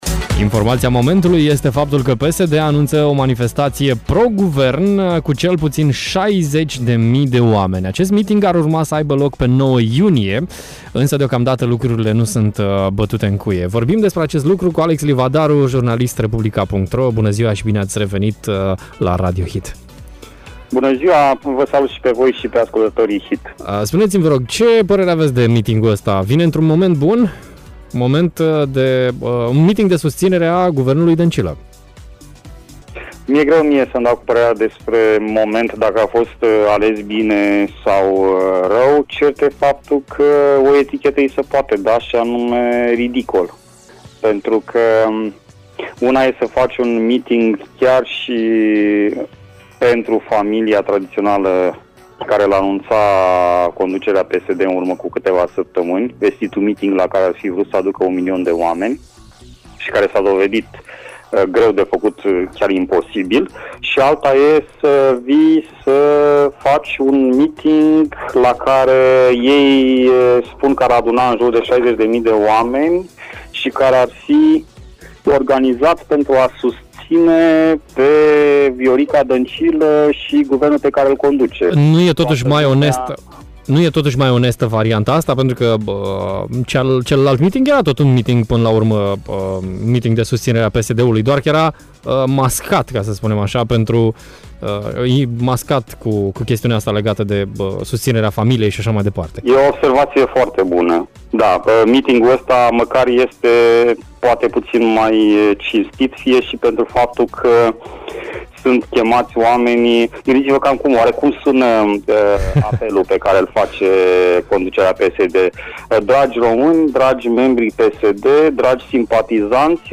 a răspuns în direct la Radio Hit la aceste întrebări și la multe altele: